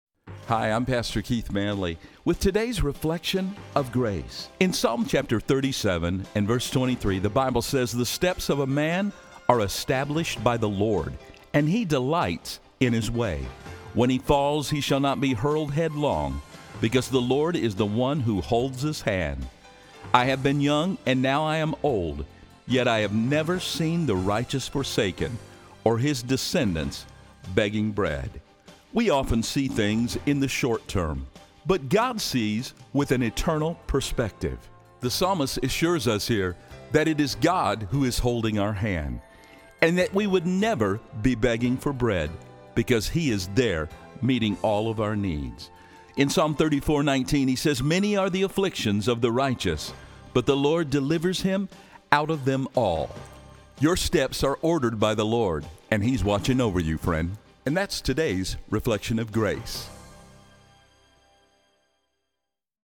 These spots air locally on 93.3 FM and on the Wilkins Radio Network heard in 27 Radio Stations around the country.